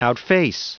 Prononciation du mot outface en anglais (fichier audio)
Prononciation du mot : outface